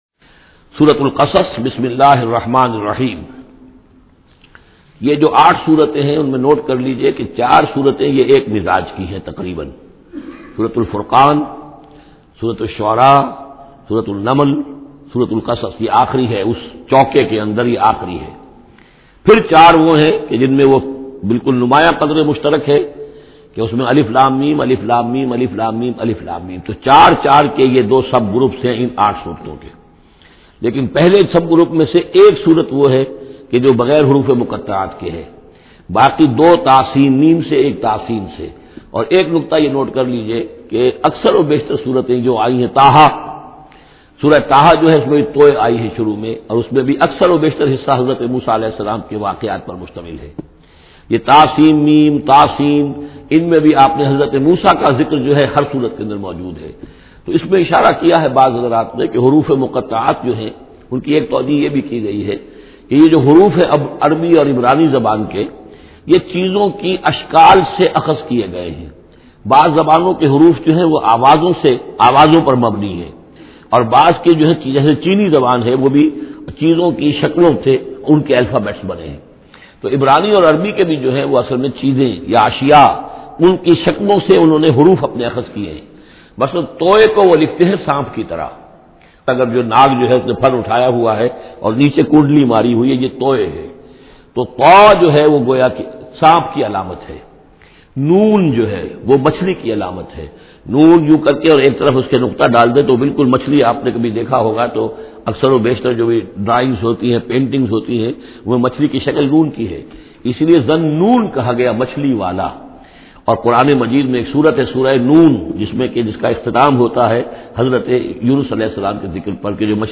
Listen online or download mp3 urdu tafseer of Surah AL Qasas in the voice of Dr Israr Ahmed.